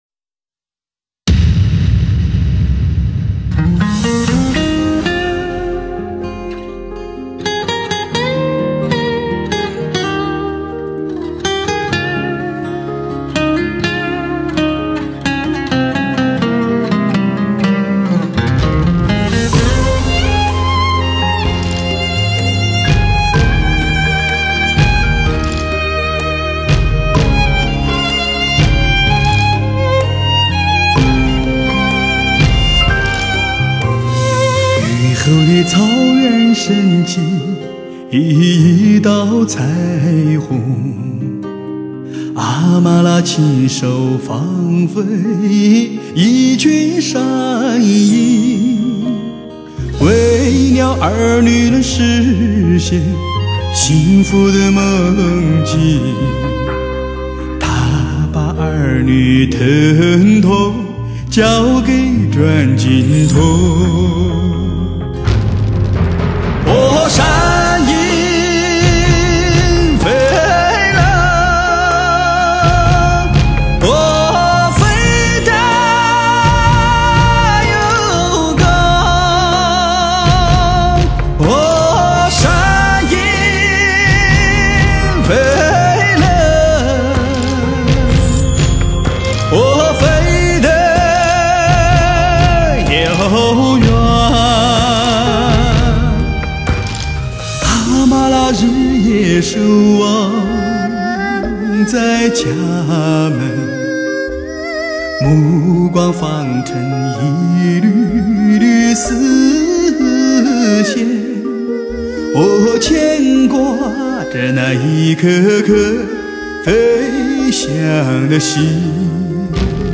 开创革命性的STS+HD全方位，环绕HI-FI AUTO SOUND专业天碟，
专有HDSTS Magix Mastering母带制作，STS Magix Virtual Live高临场感CD。